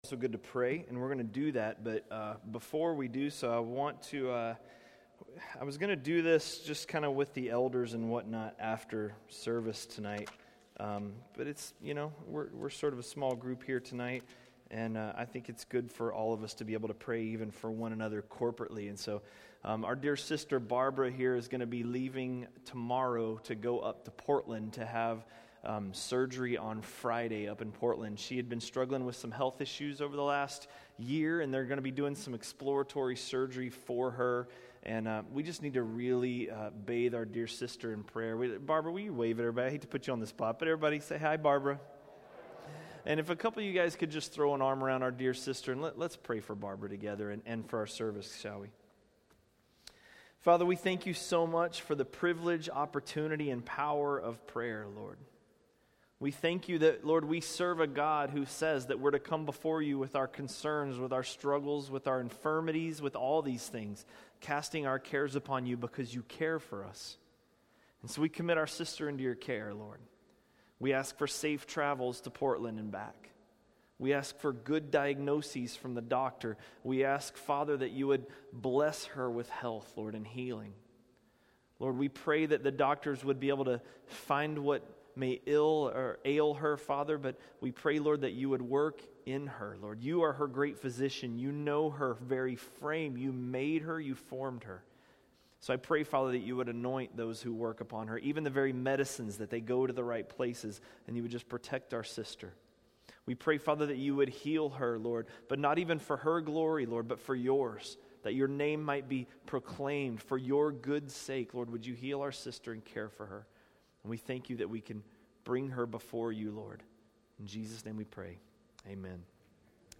A message from the series "1 Samuel." 1 Samuel 3